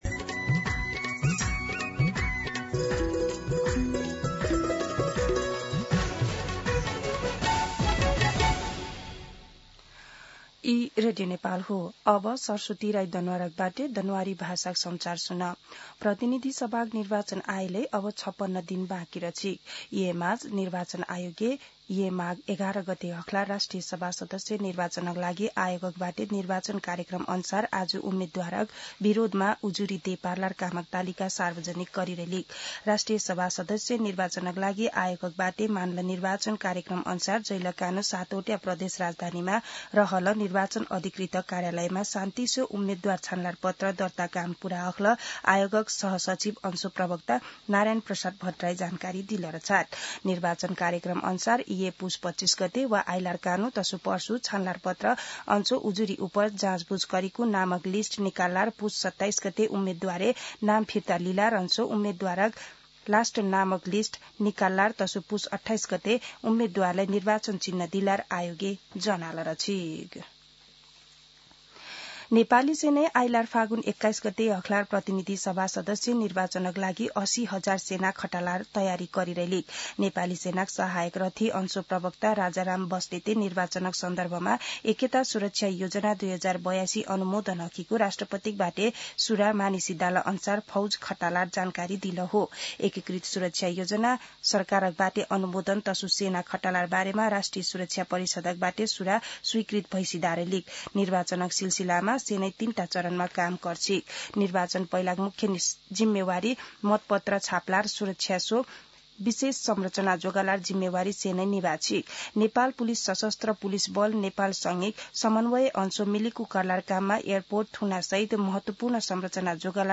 दनुवार भाषामा समाचार : २४ पुष , २०८२
Danuwar-News-09-24.mp3